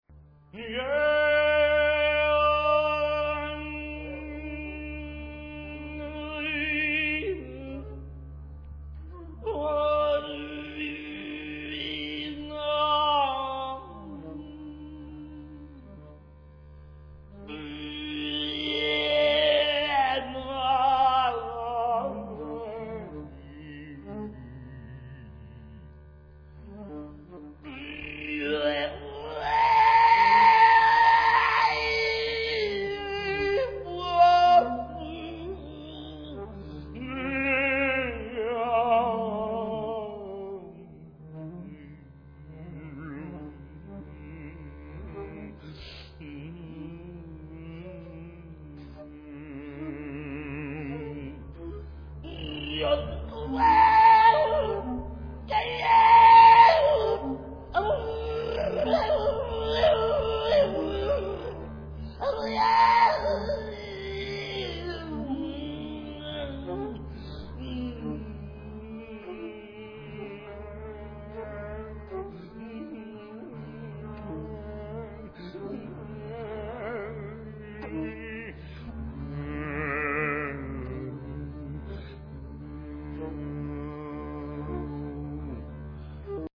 Improvisation # 1